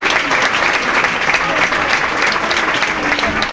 Aplausos de gente en un concierto en un pub